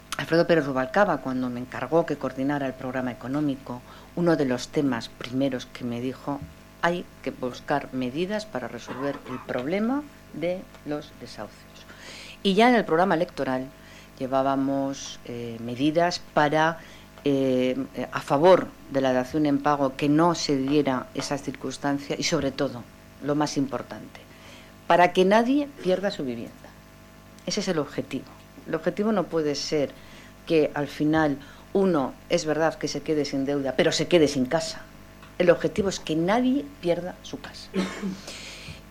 Inmaculada Rodríguez Pinero. Jornada sobre reformas hipotecarias. 13/03/2013